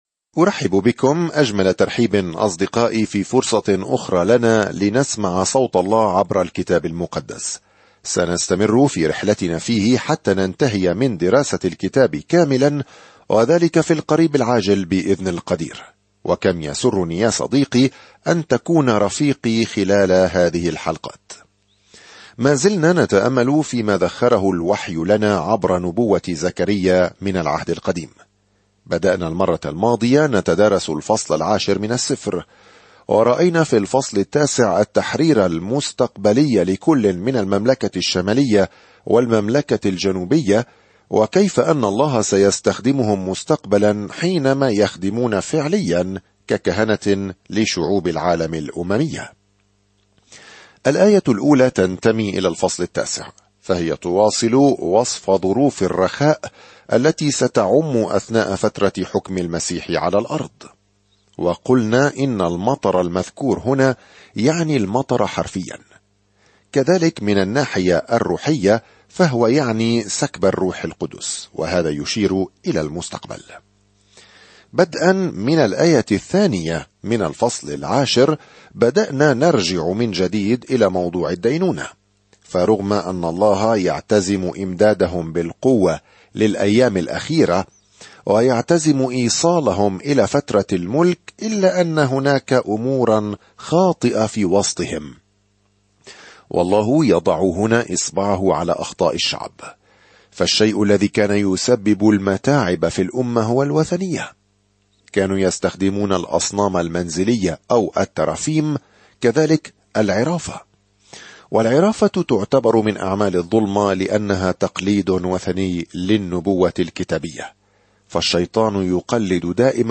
الكلمة زَكَريَّا 4:10-6 يوم 24 ابدأ هذه الخطة يوم 26 عن هذه الخطة يشارك النبي زكريا رؤى وعود الله بمنح الناس رجاء بالمستقبل ويحثهم على العودة إلى الله. سافر يوميًا عبر زكريا وأنت تستمع إلى الدراسة الصوتية وتقرأ آيات مختارة من كلمة الله.